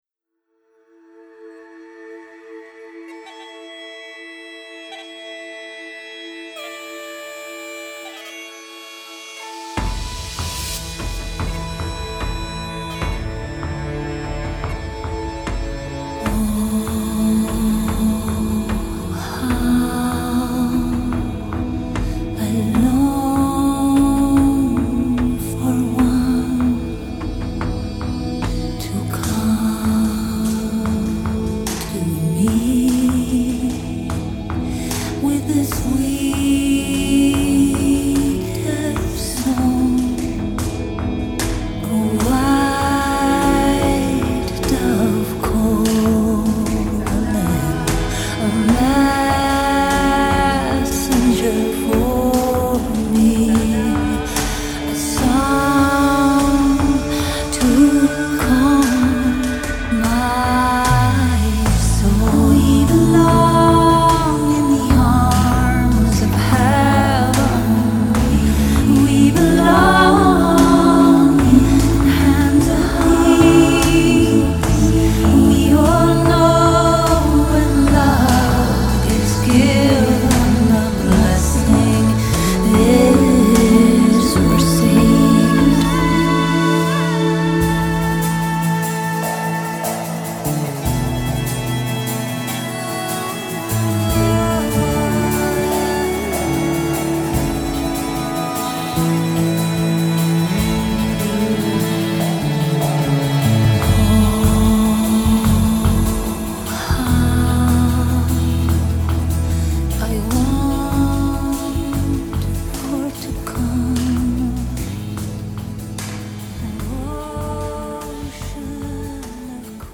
Performed Bass on
An uplifting song
with strong world feel